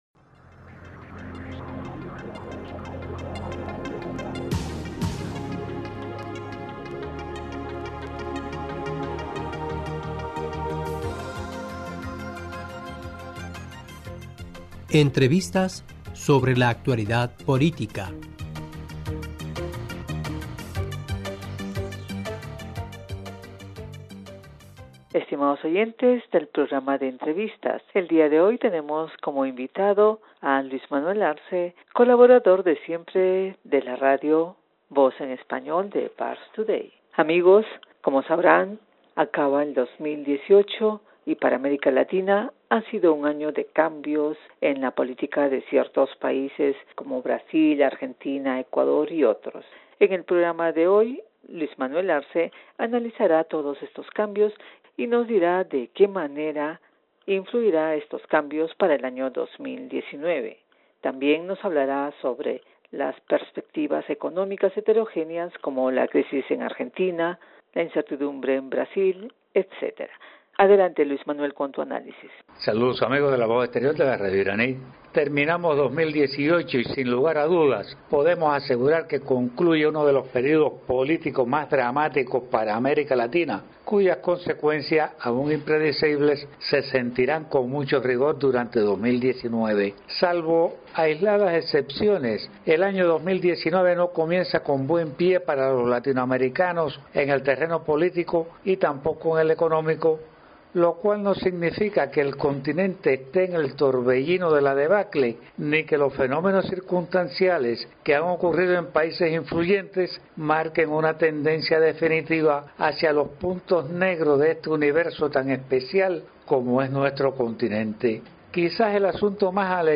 E: Estimados oyentes del programa de entrevistas.